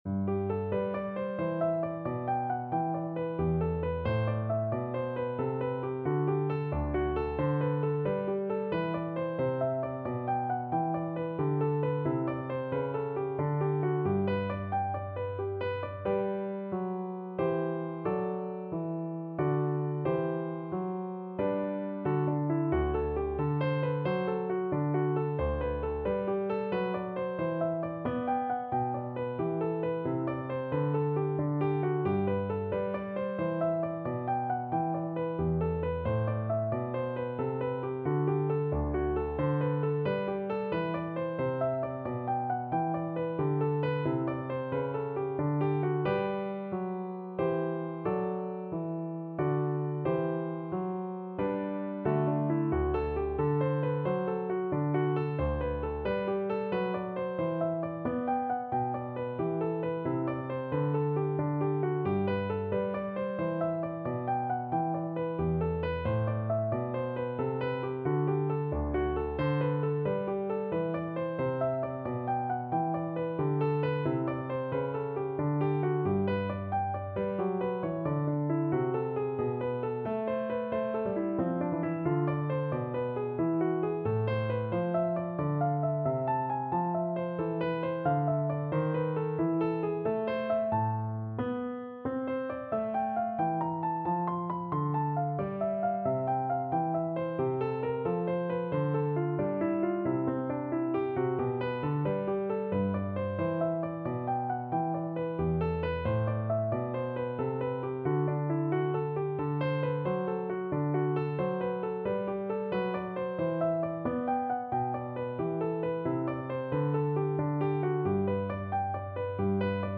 • Unlimited playalong tracks